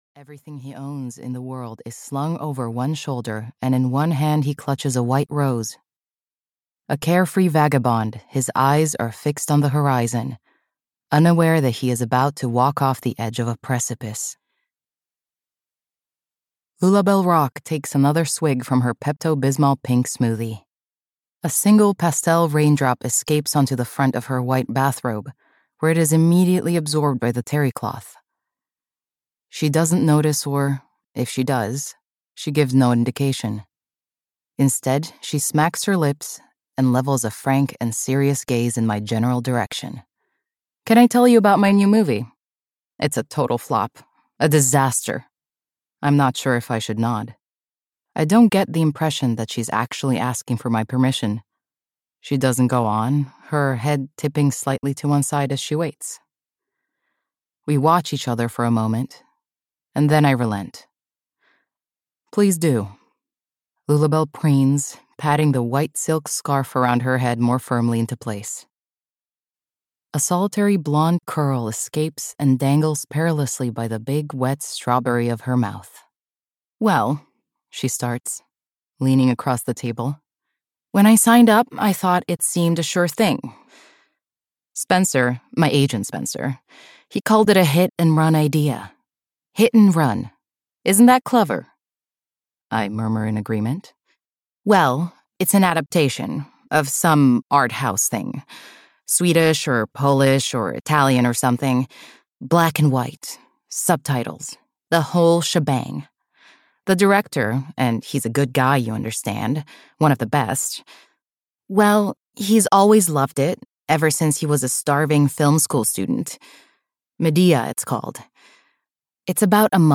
Thirteen Ways to Kill Lulabelle Rock (EN) audiokniha
Ukázka z knihy